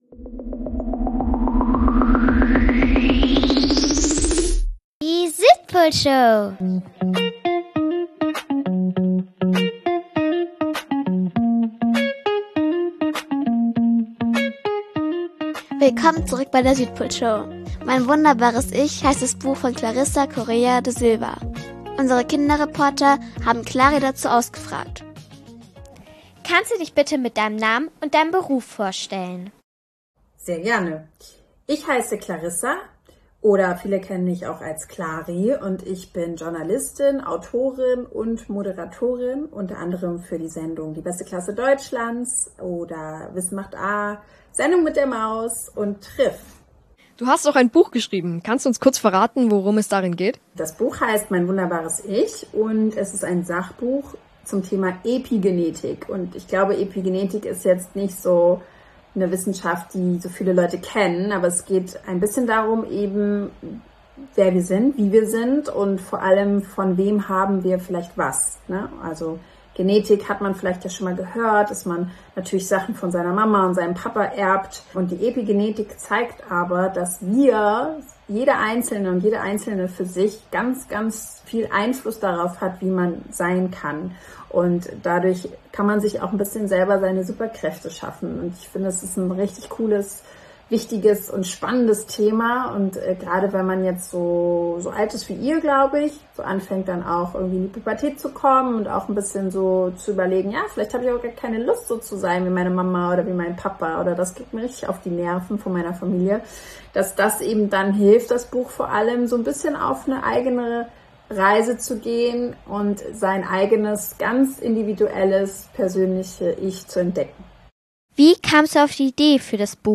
Im Interview beantwortet die Autorin alle